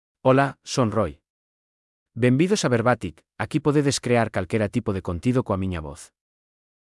RoiMale Galician AI voice
Roi is a male AI voice for Galician.
Voice sample
Listen to Roi's male Galician voice.
Male
Roi delivers clear pronunciation with authentic Galician intonation, making your content sound professionally produced.